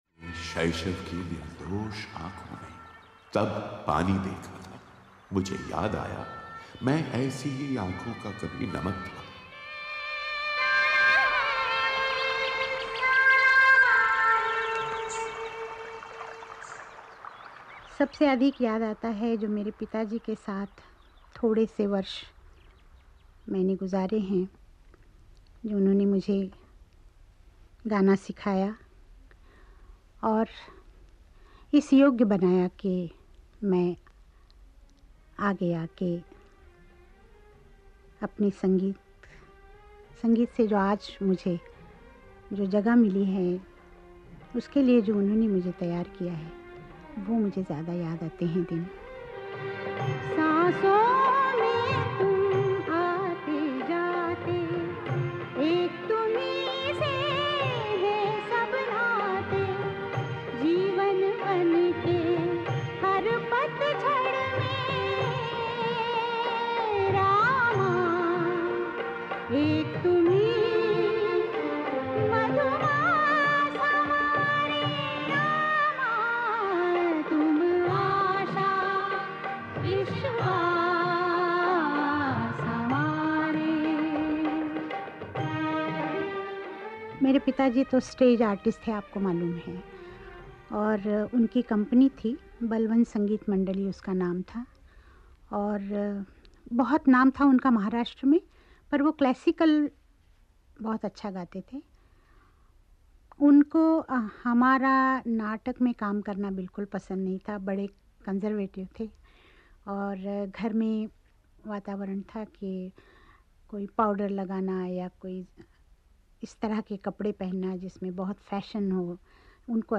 लता मंगेशकर को संगीत की सबसे पहली शिक्षा अपने पिता से मिली थी. यहां वो उनके साथ बिताए समय को याद करते हुए कह रही हैं कि कैसे उन्होंने अपने पिता से संगीत की बारीकियां सीखीं